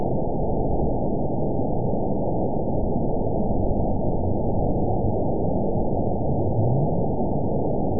event 913853 date 04/22/22 time 16:12:14 GMT (3 years ago) score 9.49 location TSS-AB01 detected by nrw target species NRW annotations +NRW Spectrogram: Frequency (kHz) vs. Time (s) audio not available .wav